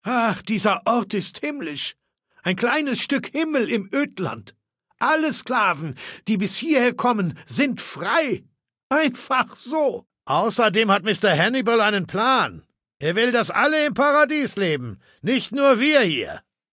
Maleold01_ms06_ms06templeunion_00027f8b.ogg (OGG-Mediendatei, Dateigröße: 135 KB.
Fallout 3: Audiodialoge